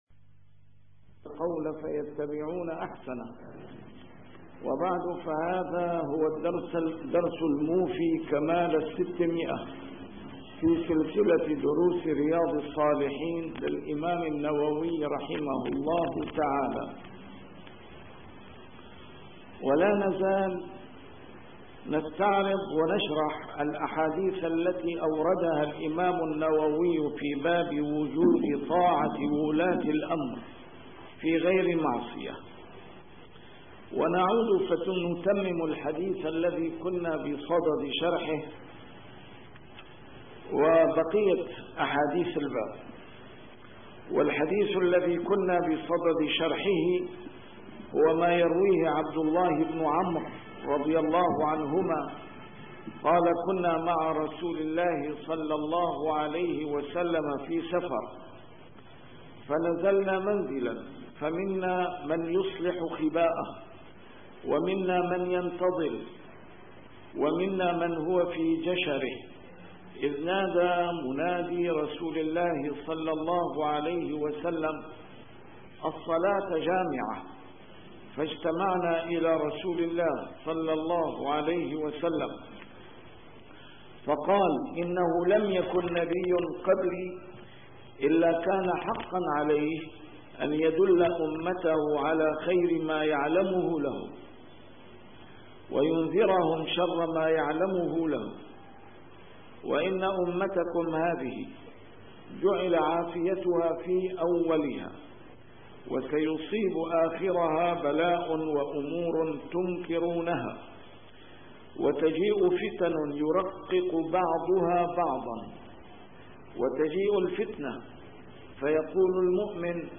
A MARTYR SCHOLAR: IMAM MUHAMMAD SAEED RAMADAN AL-BOUTI - الدروس العلمية - شرح كتاب رياض الصالحين - 600- شرح رياض الصالحين: طاعة ولاة الأمر